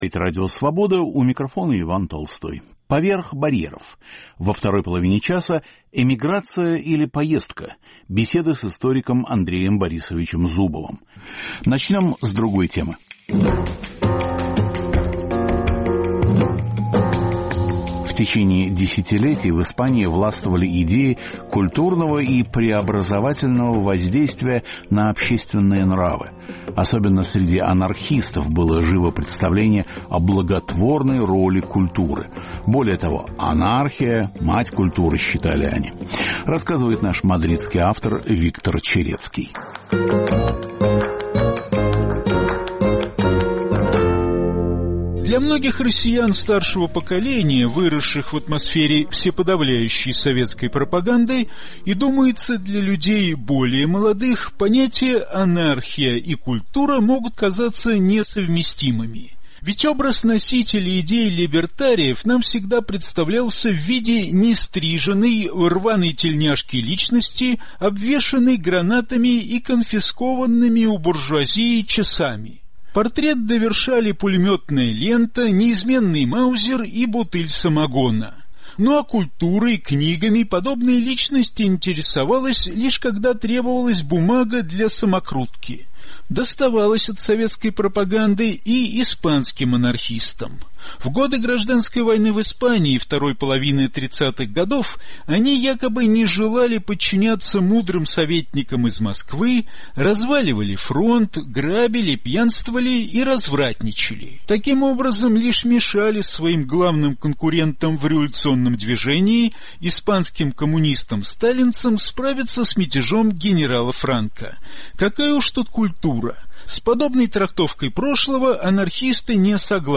Беседа с историком Андреем Зубовым.